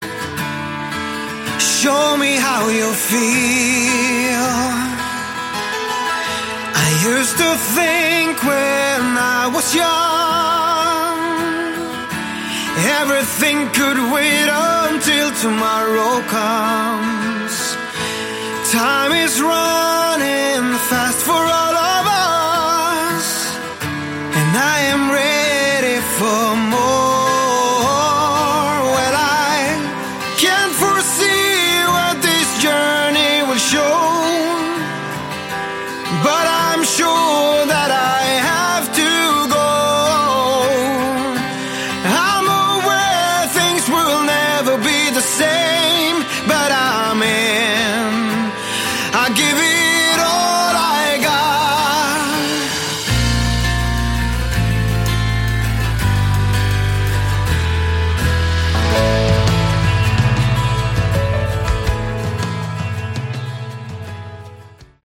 Category: AOR
keyboards, vocals
guitar, bass
drums